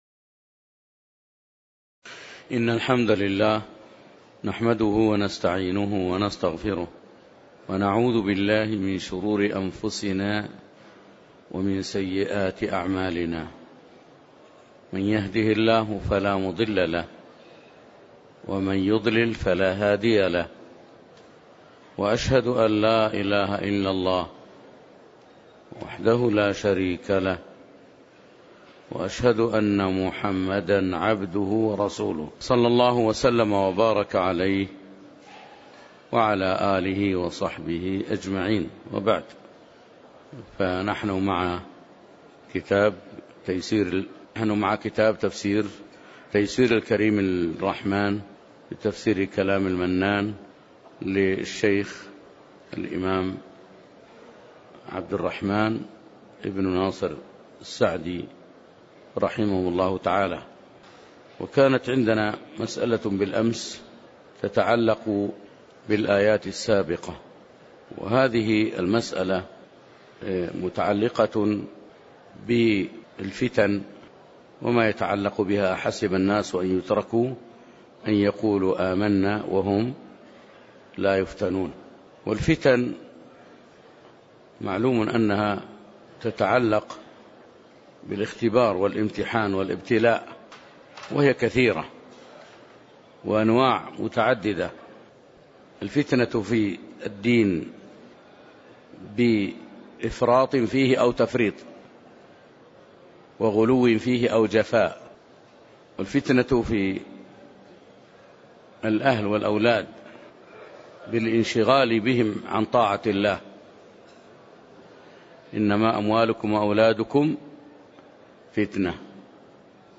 تاريخ النشر ١٠ محرم ١٤٣٩ هـ المكان: المسجد النبوي الشيخ